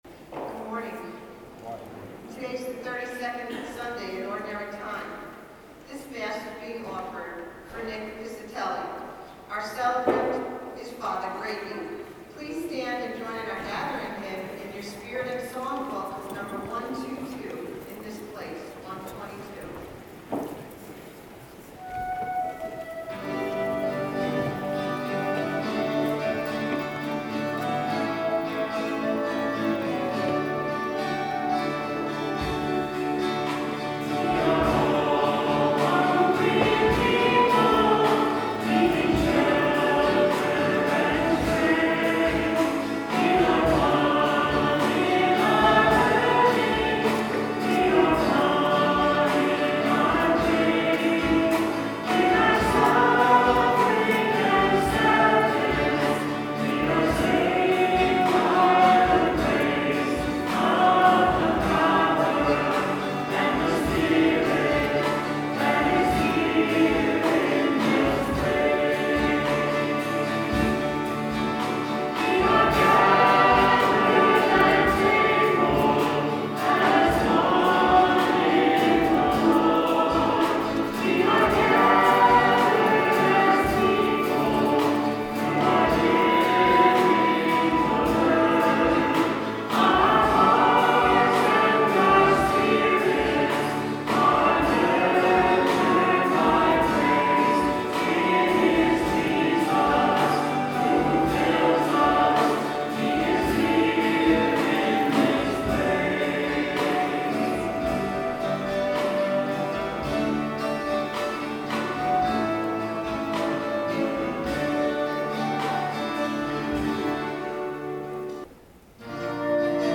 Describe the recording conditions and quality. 11/10/13 Mass Recording of Music 11/10/13 Mass Recording of Music Note that all Mass Parts have been retained in this sequence. This is the first week with our new drummer so I wanted to leave it intact. 111013 All Music.mp3 Categories : Current 2014